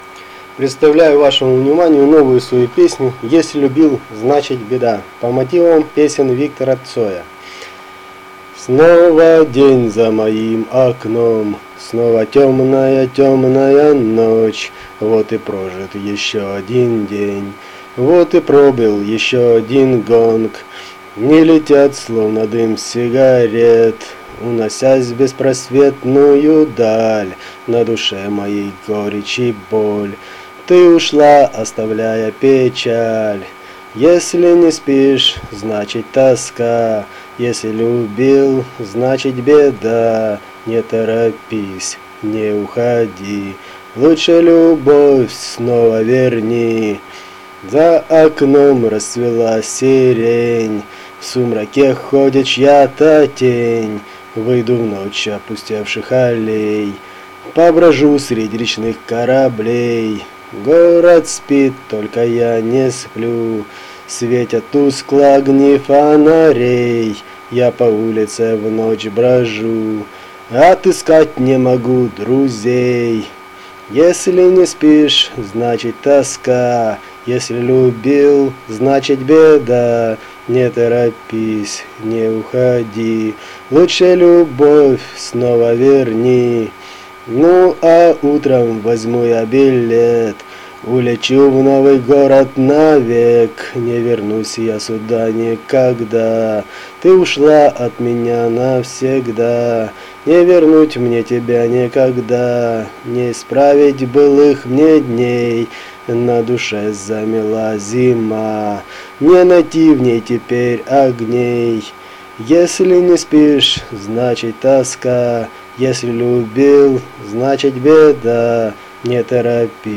По мотивам творчества Виктора Цоя.
Рубрика: Поезія, Авторська пісня